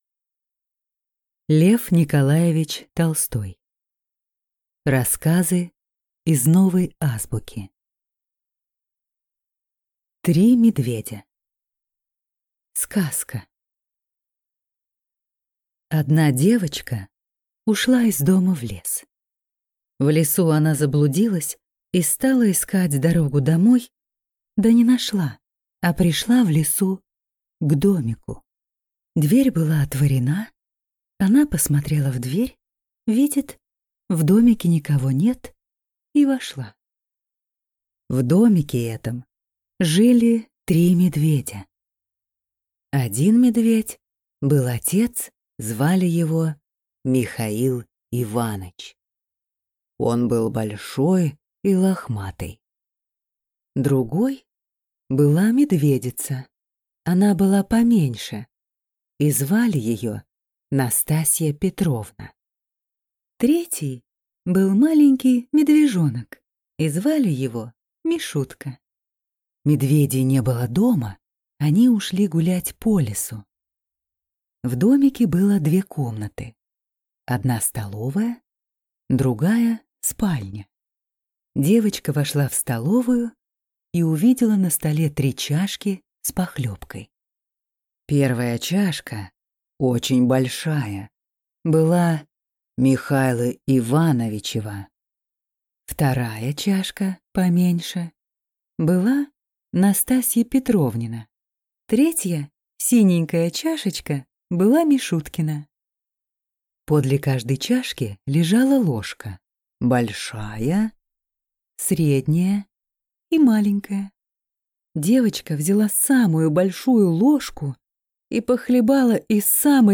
Аудиокнига Рассказы из «Новой азбуки» | Библиотека аудиокниг